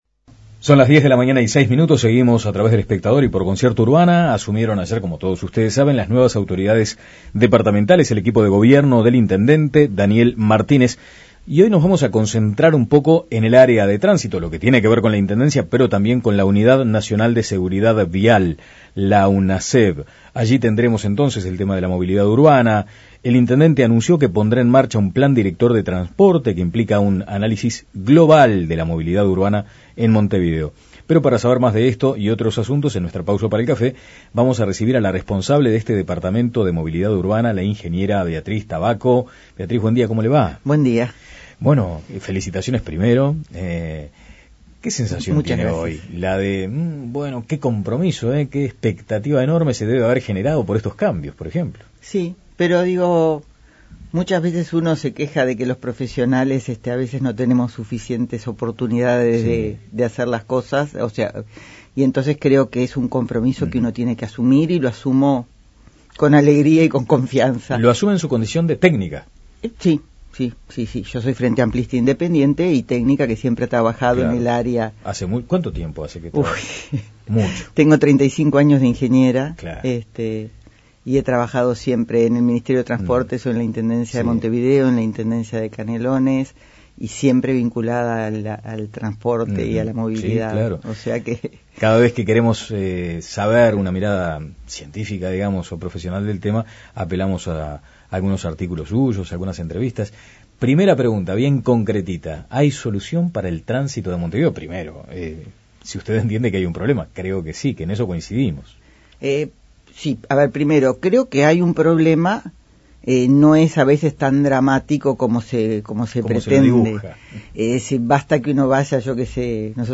Recibimos a la responsable del departamento de Movilidad Urbana, la ingeniera Beatriz Tabacco y al secretario general de la Unidad Nacional de Seguridad Vial (Unasev), Pablo Inthamoussu.